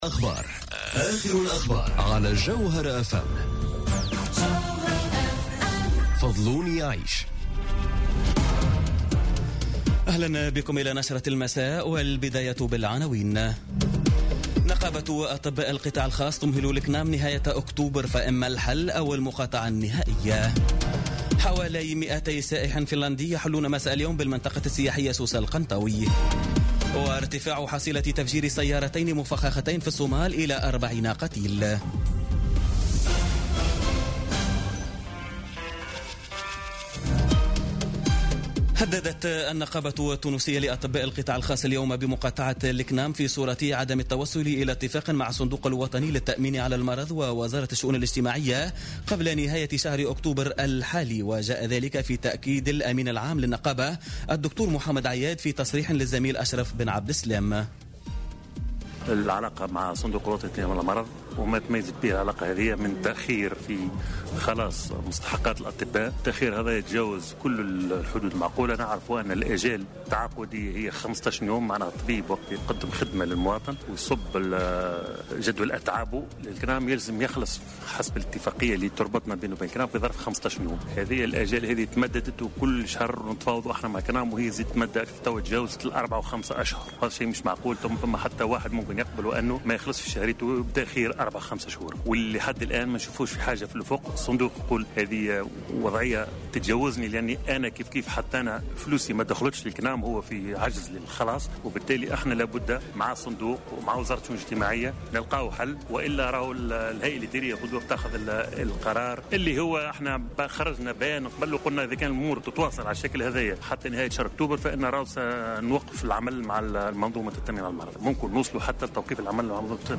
نشرة أخبار السابعة مساء ليوم السبت 14 أكتوبر 2017